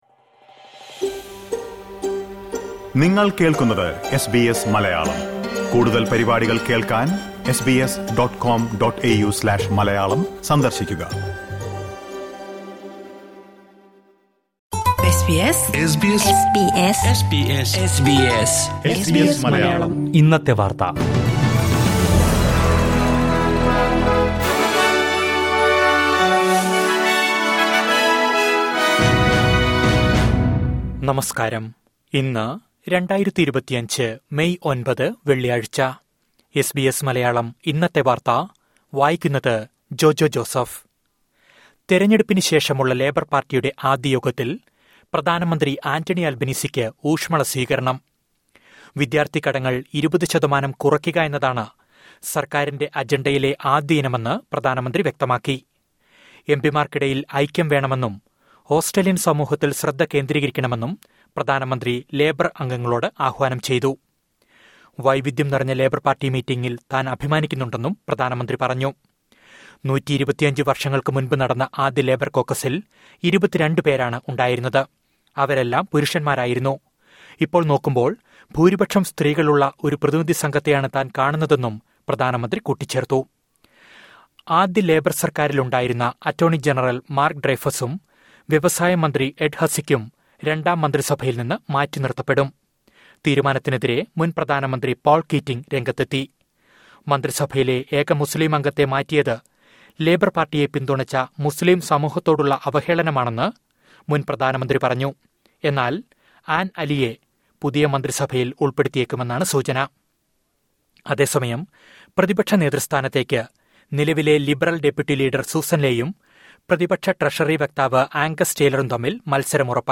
2025 മേയ് ഒമ്പതിലെ ഓസ്‌ട്രേിയയിലെ ഏറ്റവും പ്രധാന വാര്‍ത്തകള്‍ കേള്‍ക്കാം...